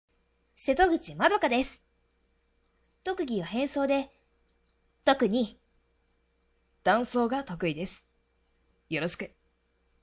性別 女性
イメージボイス